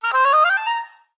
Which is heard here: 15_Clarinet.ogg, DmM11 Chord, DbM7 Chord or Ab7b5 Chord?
15_Clarinet.ogg